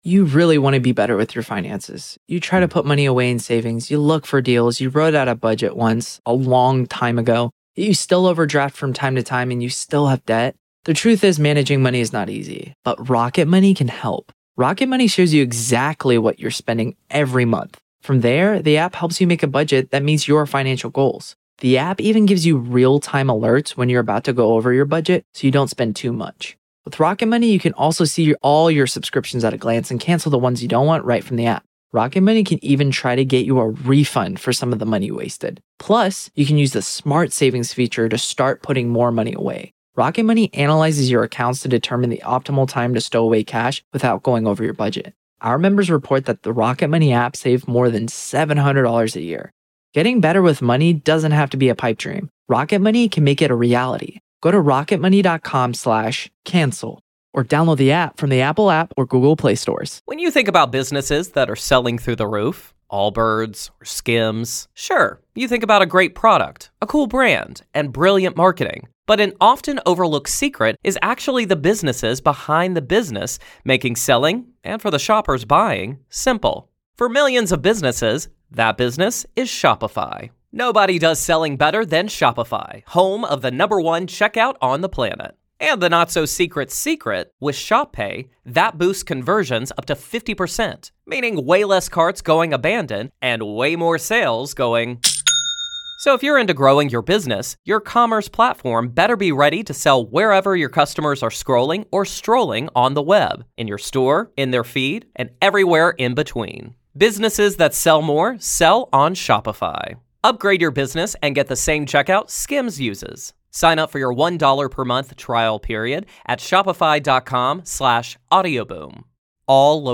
The Playful Haunting Pranks from Beyond the Grave | Real Ghost Stories LIVE